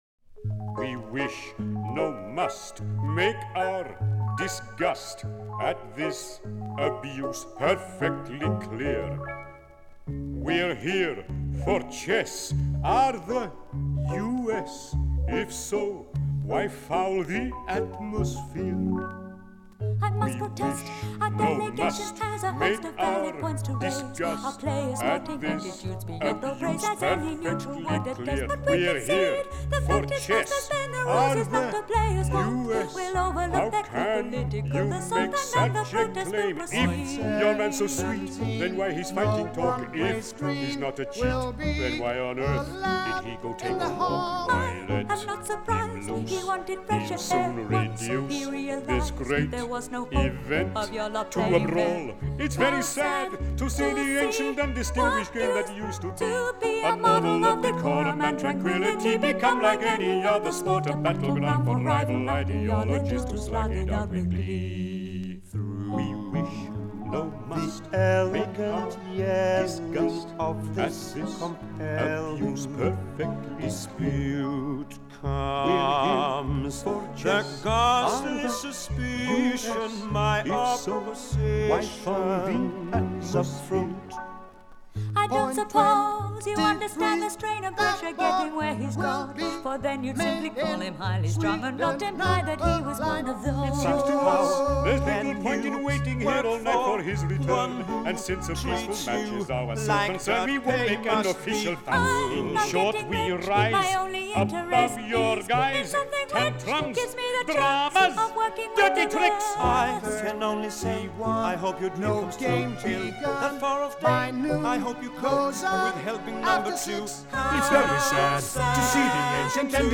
Жанр: Electronic, Rock, Pop, Stage & Screen
Стиль: Pop Rock, Musical, Ballad, Synth-pop